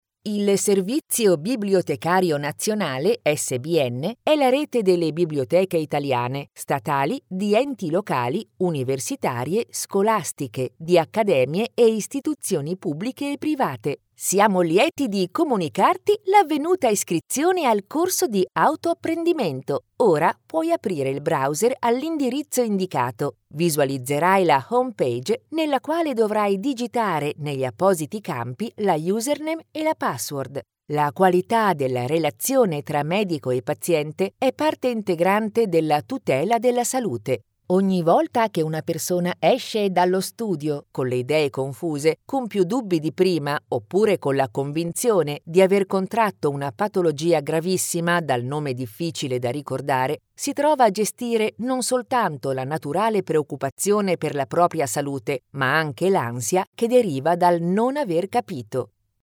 Italian female voice talent, Experienced, versatile, friendly, educated, assured.Warm and clear for narration, more young for commercial, professional and smooth for presentation
Sprechprobe: eLearning (Muttersprache):